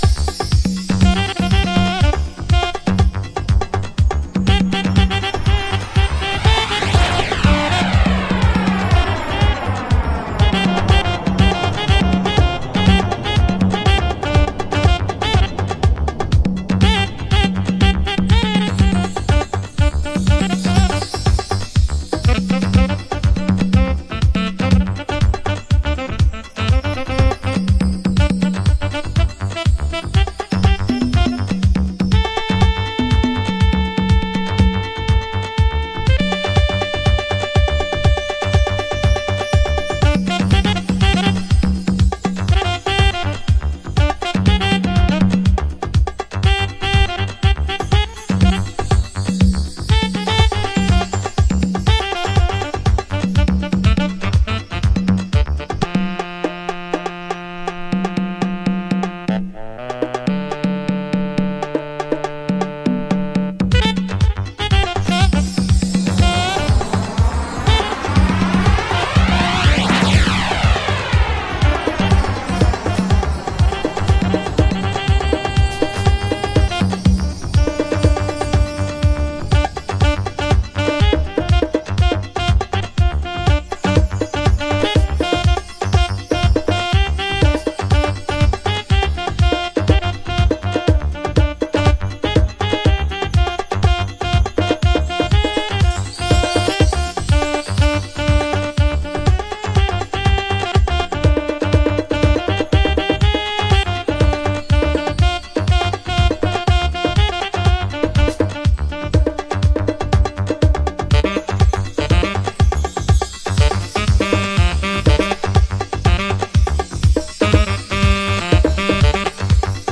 Early House / 90's Techno
NY初期ハウス古典。